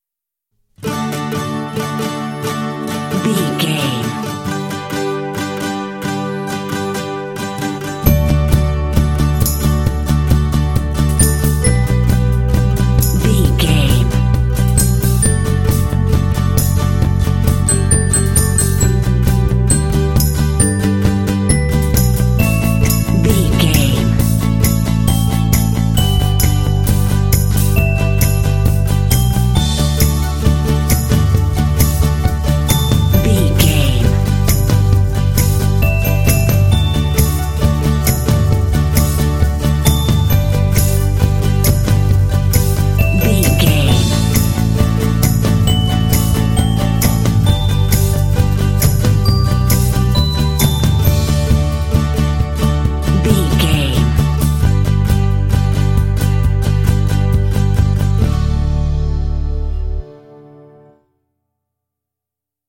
Cute and wholesome indie track, great for kids games.
Uplifting
Ionian/Major
D
bouncy
happy
groovy
bright
acoustic guitar
bass guitar
drums
percussion
pop
contemporary underscore